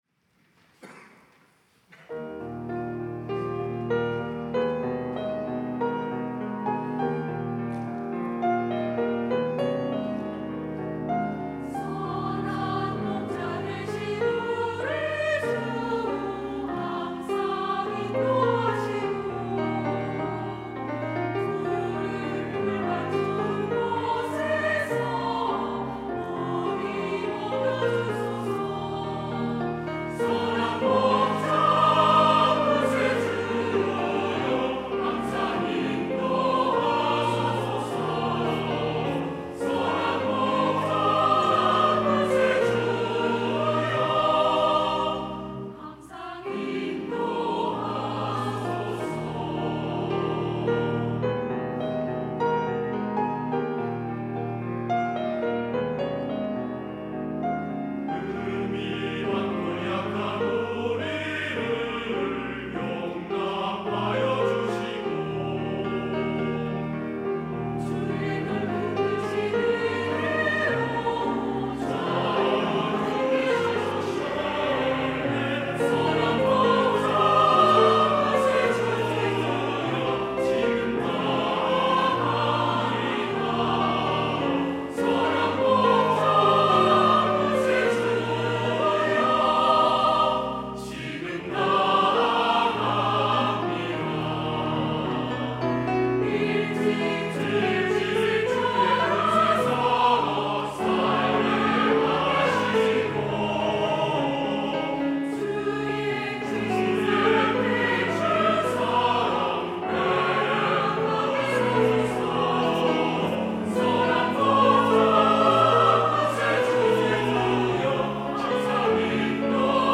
할렐루야(주일2부) - 선한 목자 되신 우리 주
찬양대